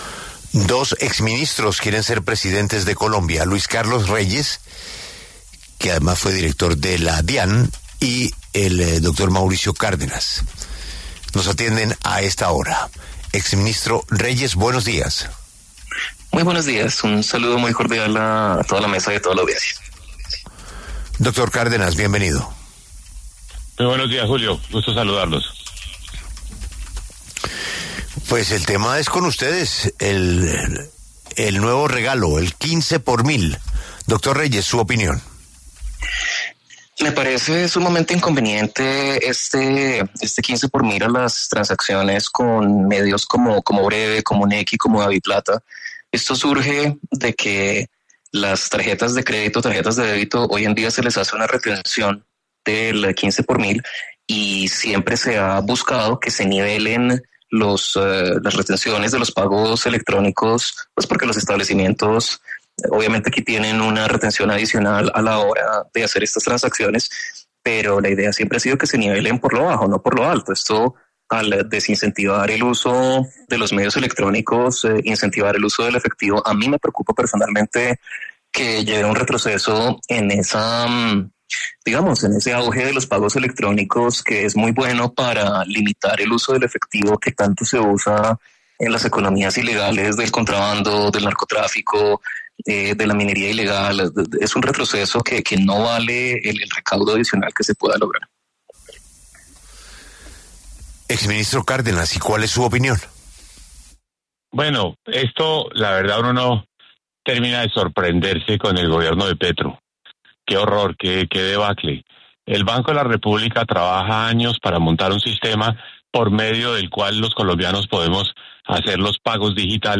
Luis Carlos Reyes y Mauricio Cárdenas tuvieron un cara a cara en La W donde hablaron del proyecto del Ministerio de Hacienda sobre Bre-B y su panorama político de cara a las elecciones del 2026.